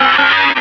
Cri de Ramboum dans Pokémon Rubis et Saphir.